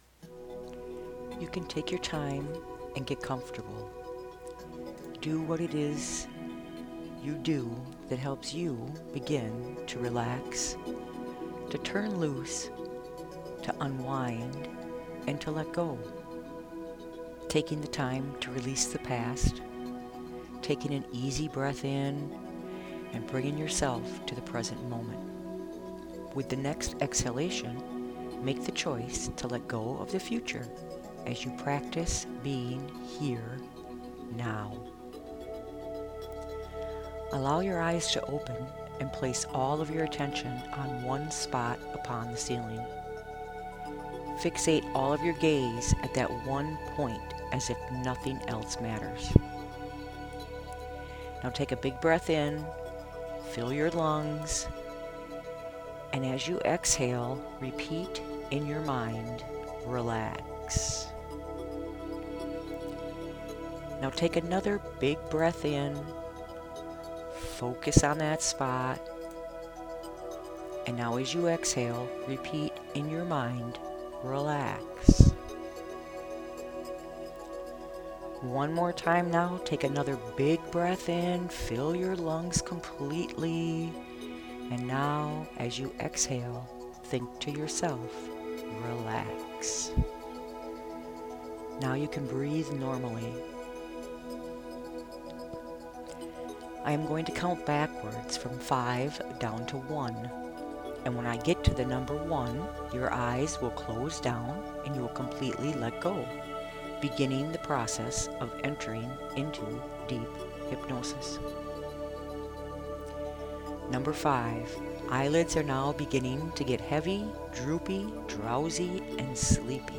Free Peace Meditation